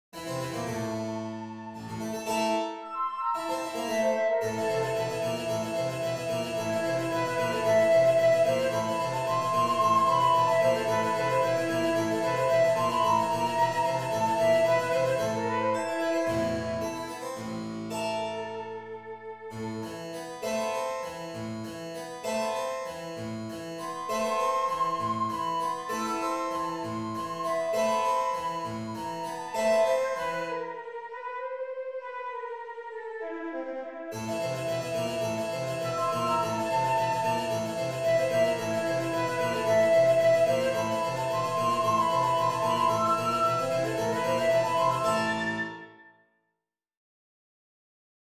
for flute and harpsichord
(per flauto e clavicembalo)